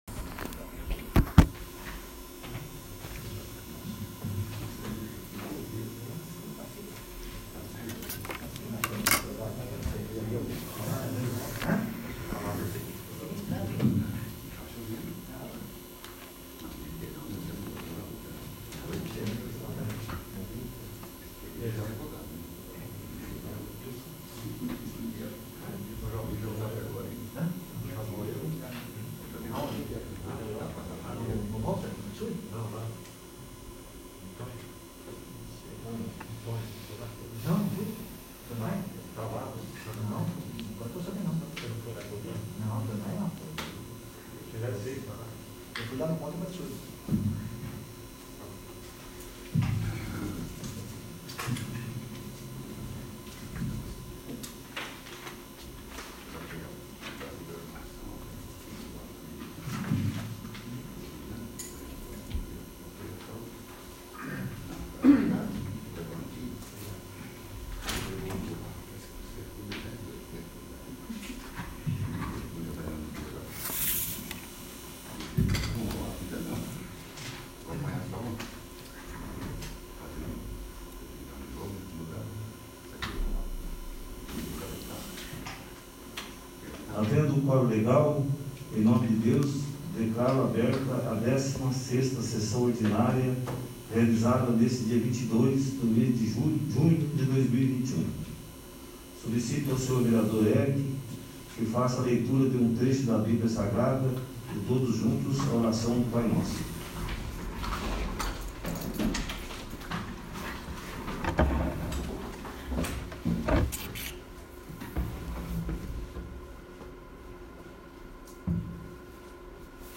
16º. Sessão Ordinária